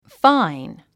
発音
fáin　ファイン